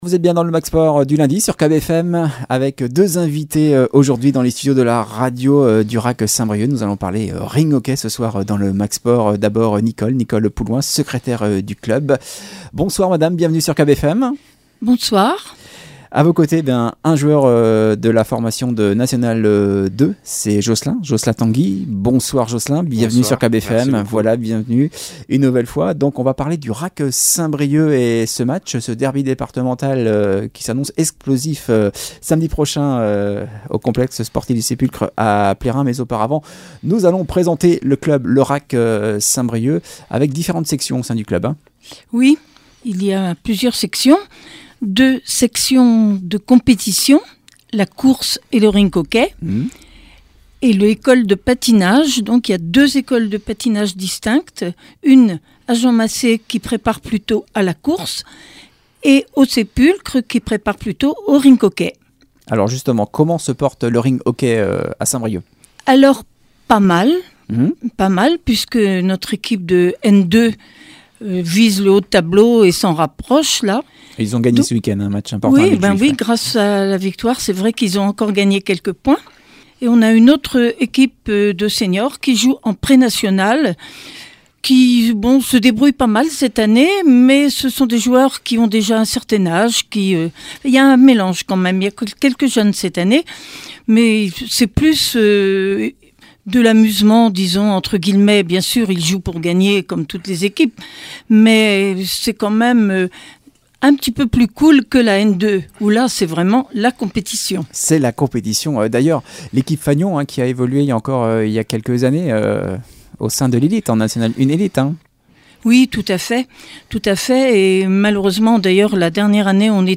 Invités du Mag Sport hier soir